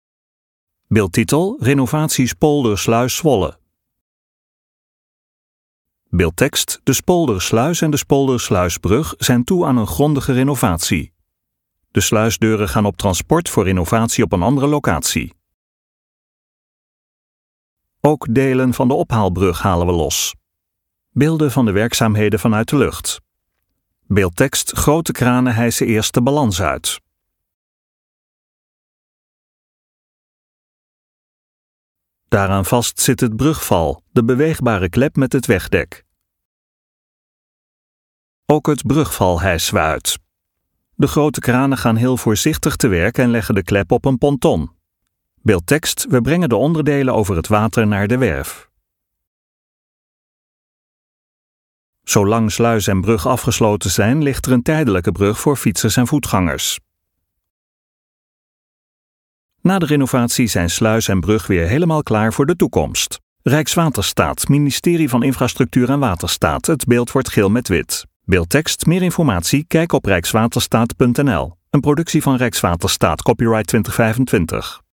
LEVENDIGE MUZIEK TOT HET EIND VAN DE VIDEO (De sluisdeuren gaan op transport voor renovatie op een andere locatie.